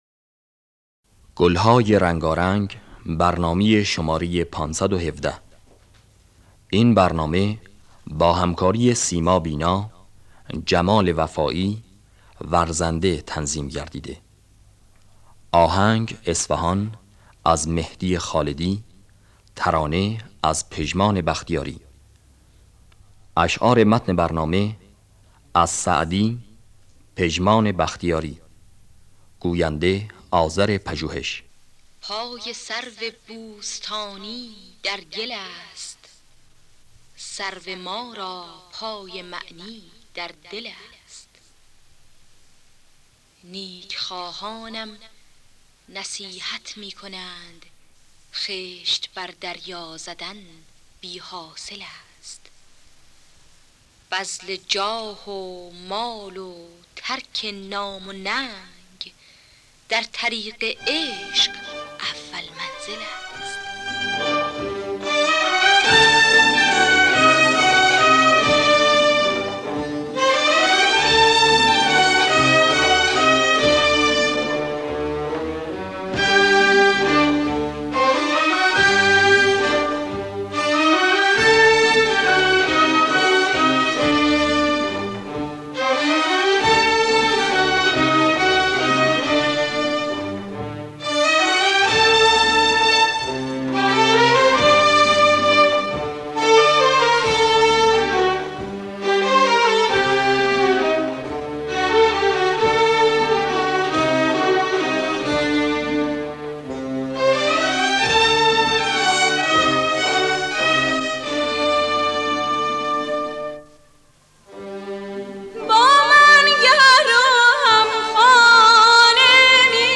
دانلود گلهای رنگارنگ ۵۱۷ - آرشیو کامل برنامه‌های رادیو ایران